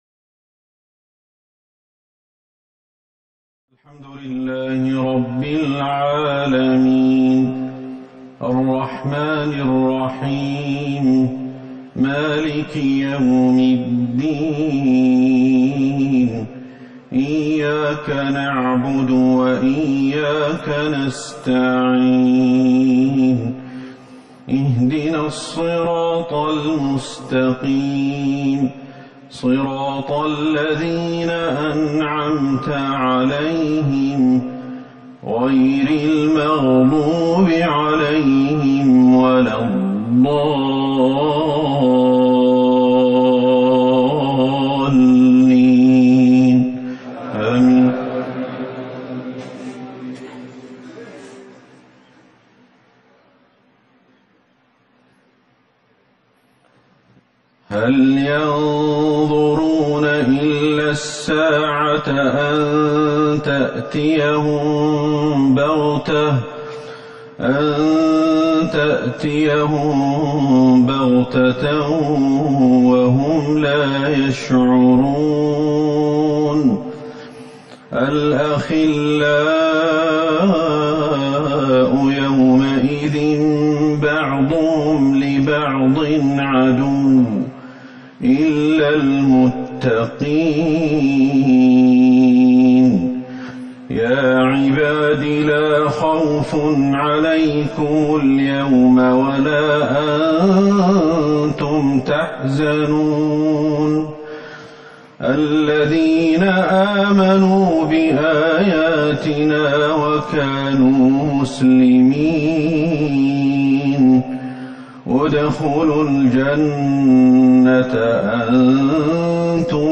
صلاة العشاء ١٠ جمادي الاخره ١٤٤١هـ سورة الزخرف Isha prayer 4-2-2020 from Surah Az-Zukhruf > 1441 🕌 > الفروض - تلاوات الحرمين